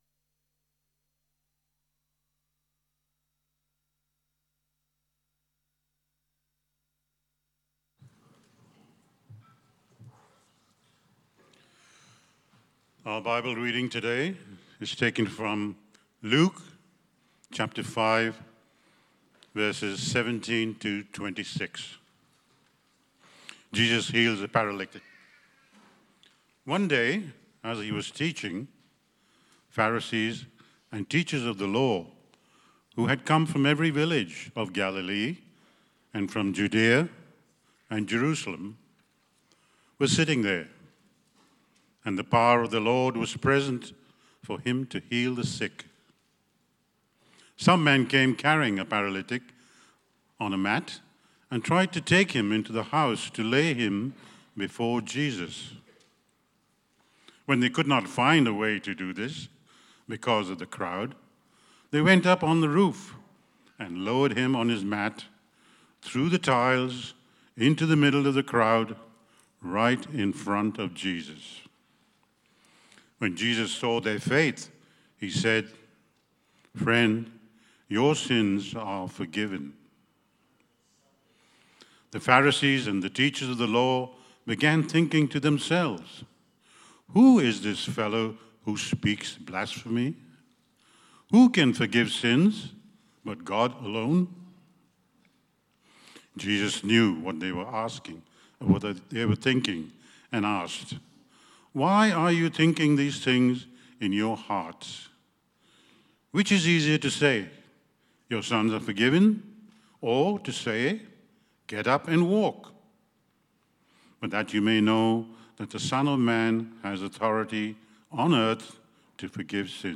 Service Type: AM
Vision-Sunday-Sermon.mp3